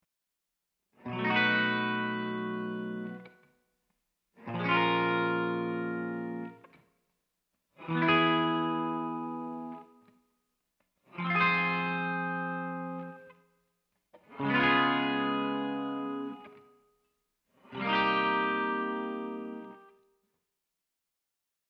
FIGURE 3 depicts an arbitrary sequence of major, minor, major seventh and minor seventh chords.